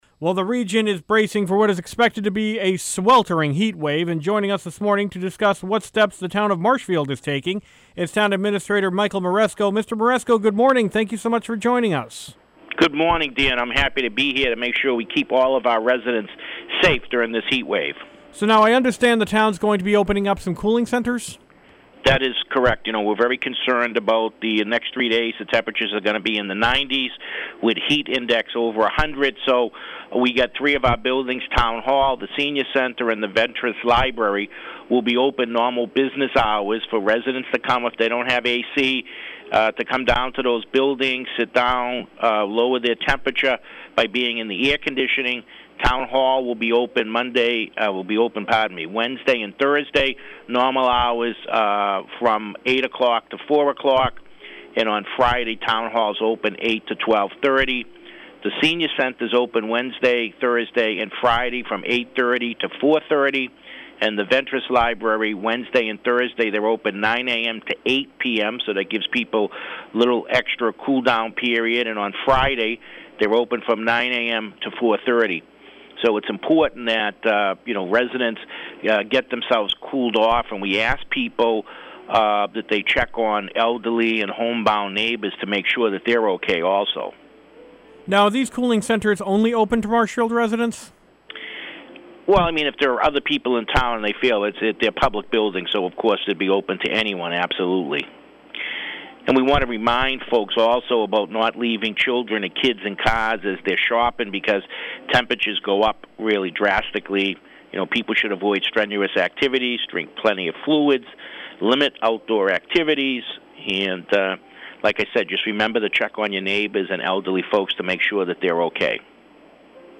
Marshfield Town Administrator Michael Maresco speaks